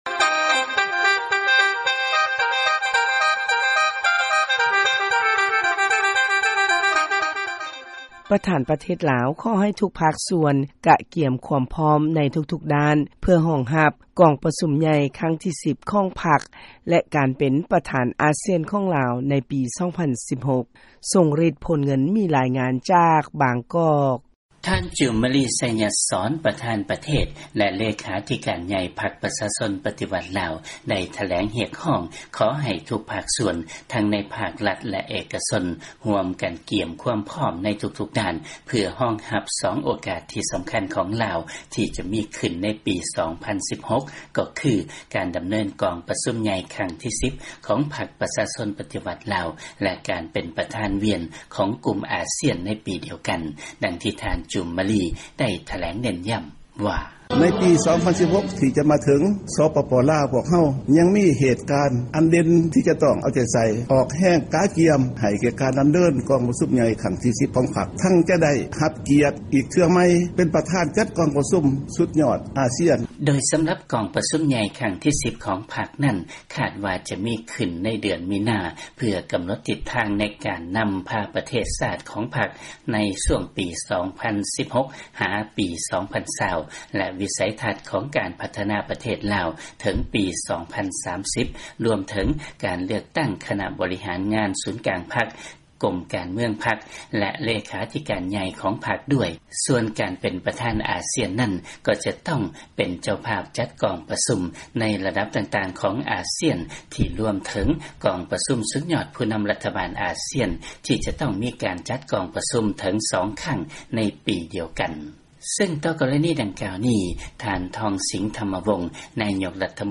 ຟັງລາຍງານ ລາວ ກະກຽມຄວາມພ້ອມ ເພື່ອຮອງຮັບ ກອງປະຊຸມໃຫຍ່ຄັ້ງທີ 10 ຂອງພັກ ແລະ ການເປັນປະທານອາຊ່ຽນນຳ.